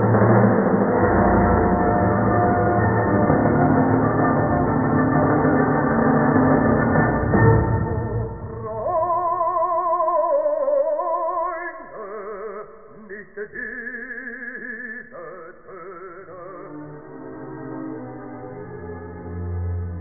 bassa (qualità telefono),